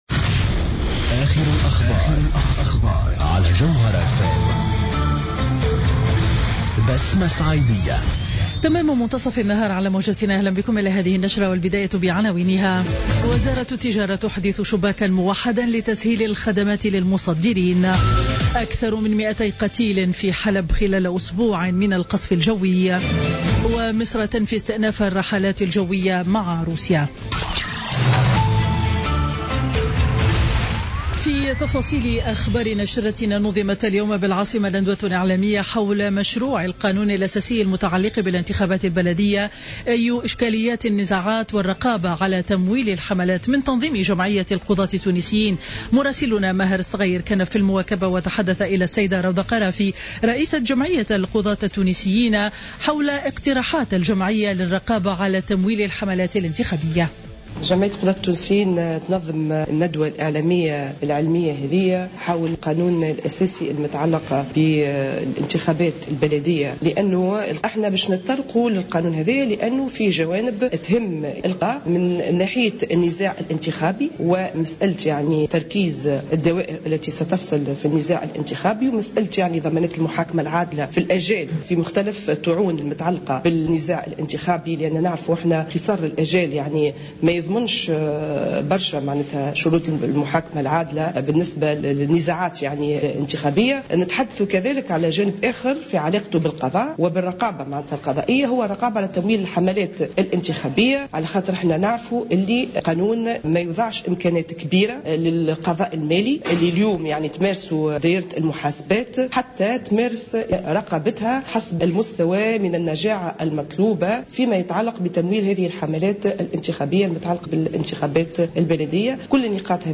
نشرة أخبار منتصف النهار ليوم الجمعة 29 أفريل 2016